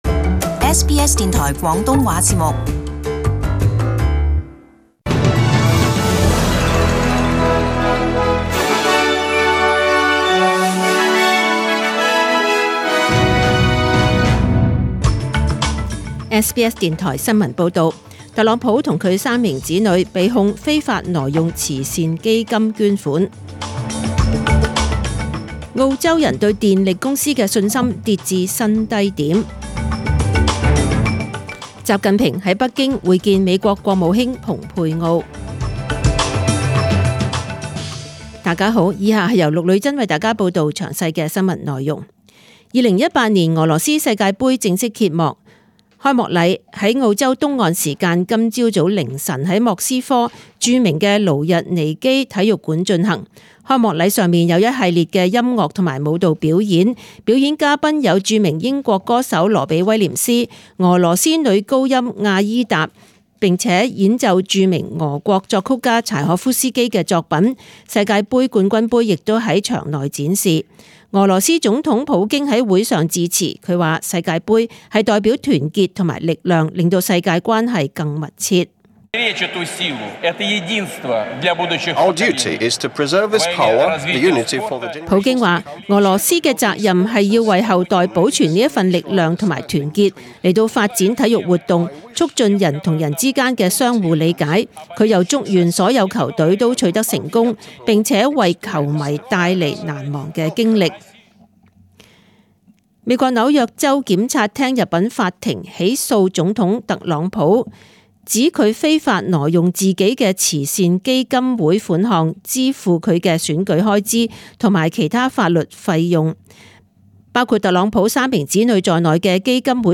SBS中文新闻 （六月十五日）